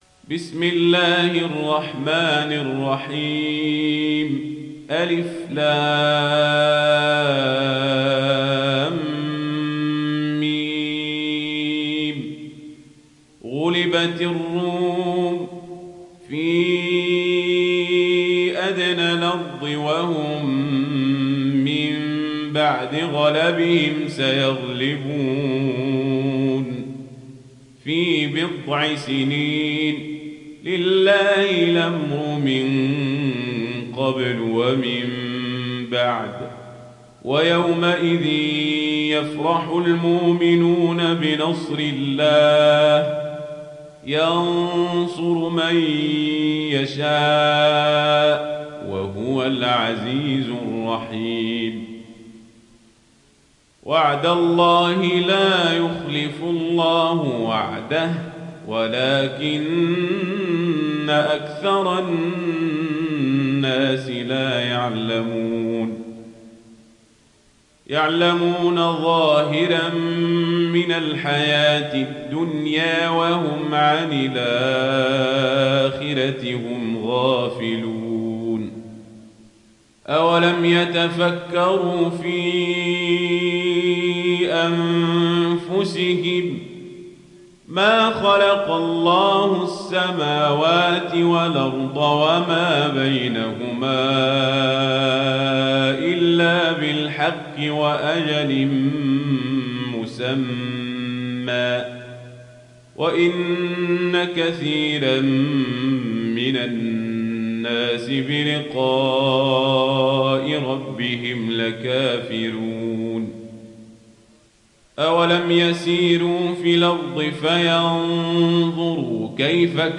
دانلود سوره الروم mp3 عمر القزابري روایت ورش از نافع, قرآن را دانلود کنید و گوش کن mp3 ، لینک مستقیم کامل